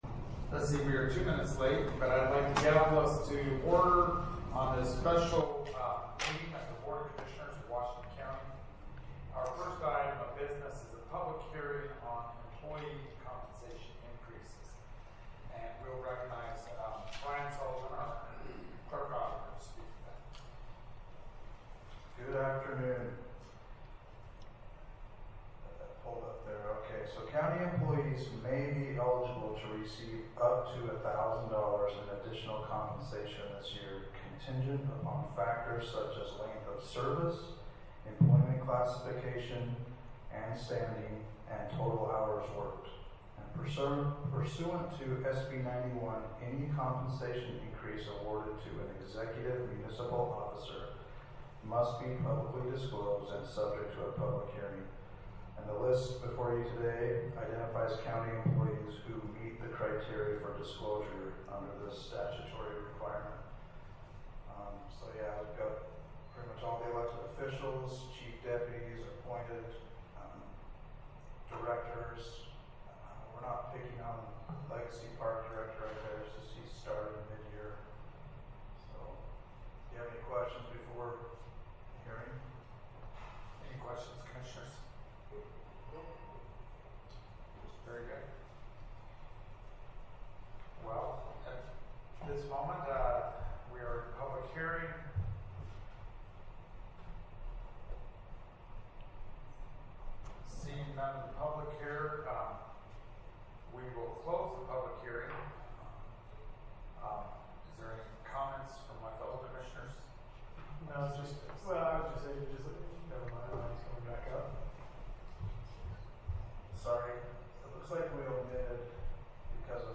Notice of Special Meeting of the Board of Commissioners of Washington County, Utah
111 East Tabernacle